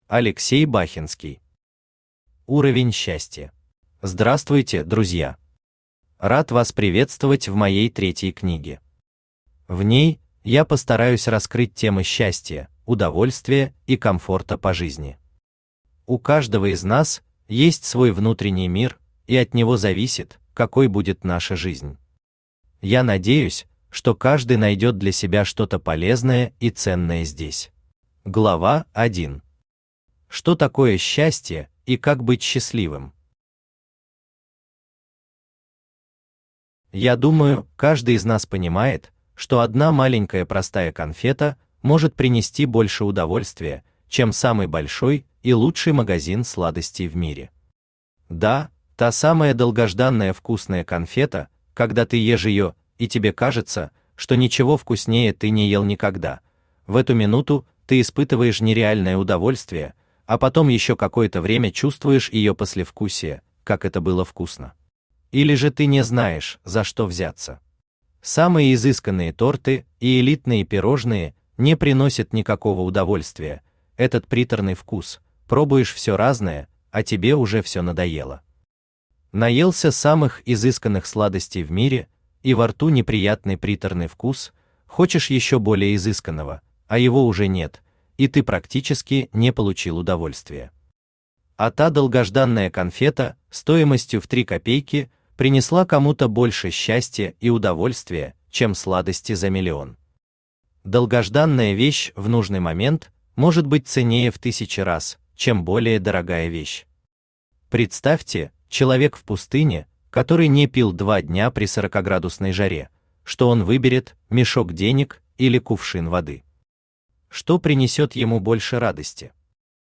Аудиокнига Уровень счастья | Библиотека аудиокниг
Aудиокнига Уровень счастья Автор Алексей Бахенский Читает аудиокнигу Авточтец ЛитРес.